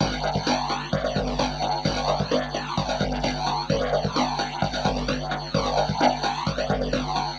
酸性突破循环 130 Bpm
Tag: 130 bpm Breakbeat Loops Fx Loops 1.24 MB wav Key : Unknown